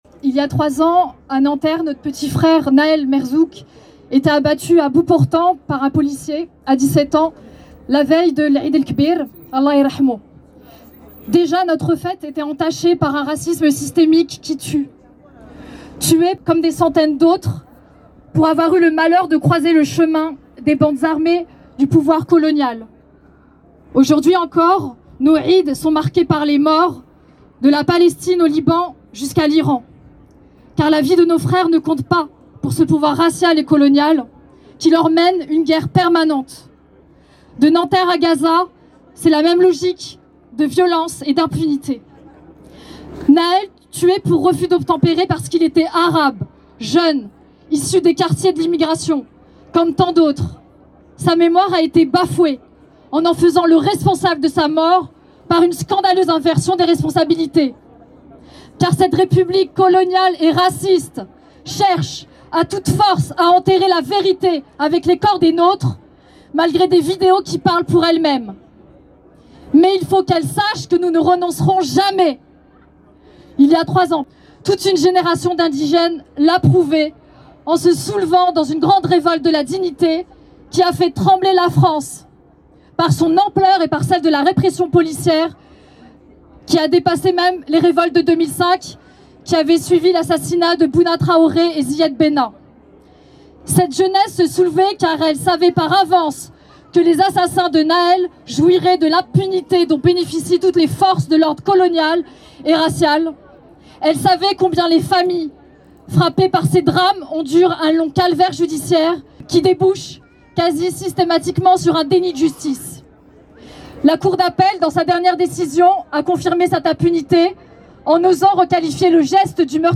Intervention du PIR à la Marche pour Nahel, pour nos jeunes et contre l’impunité policière du 21 mars 2026 à Nanterre.